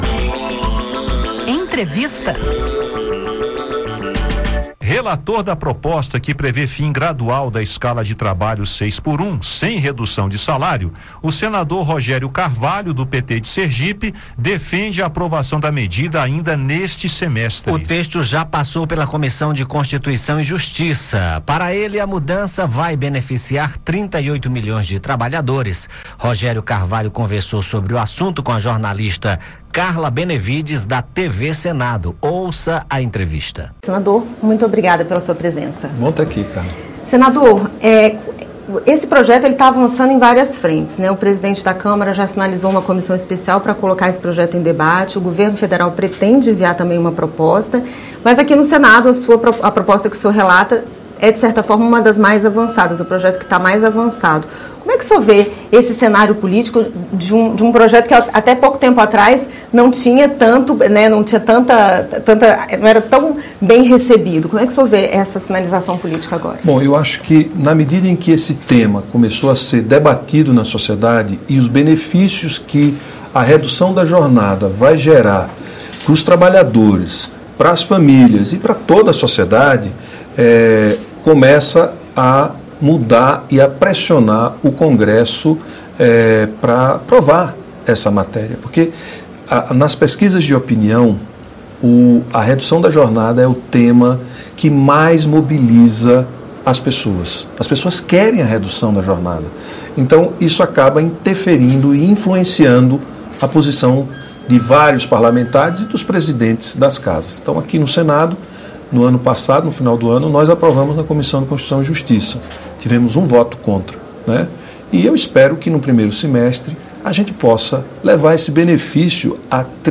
O senador Rogério Carvalho (PT-SE), que é o relator da proposta, defende a aprovação da medida e afirma que a mudança vai beneficiar 38 milhões de trabalhadores. A expectativa do relator é que a PEC seja aprovada no primeiro semestre de 2026. Acompanhe a entrevista.